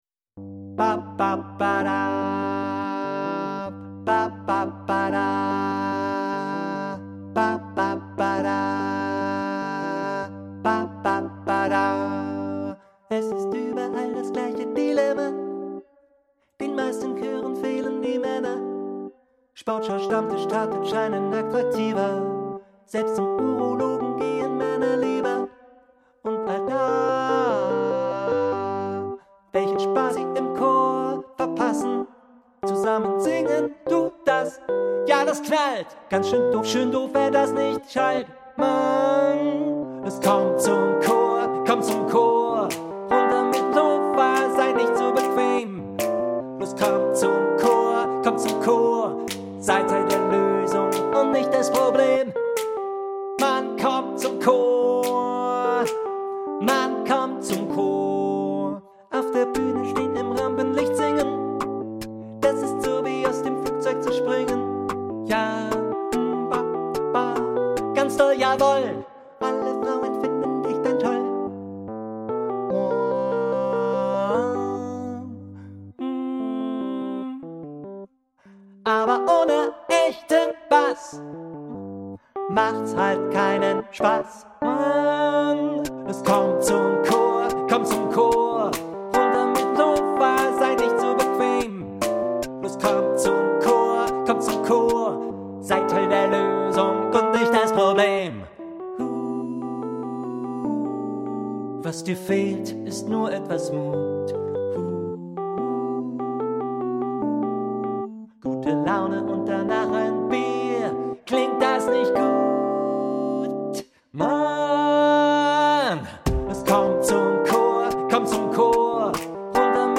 • Besetzung: 4-stimmig, a cappella
Tenorstimme